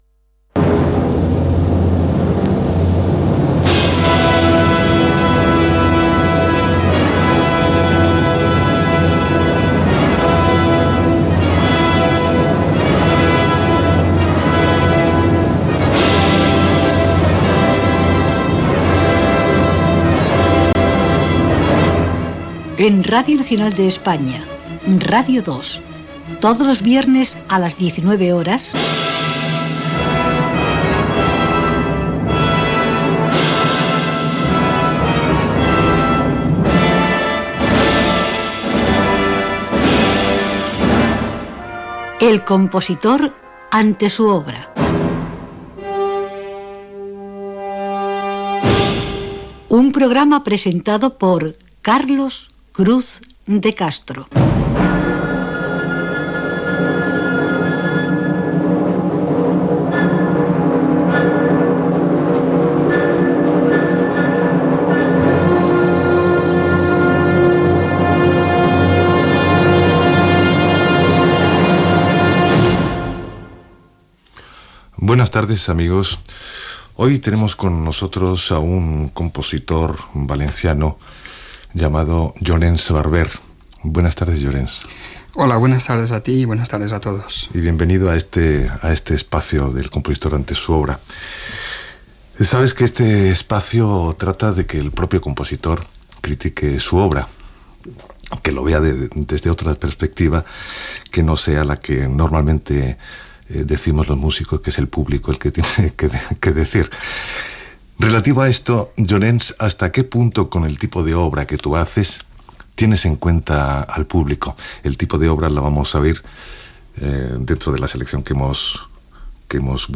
Careta del programa, presentació i entrevista al compositor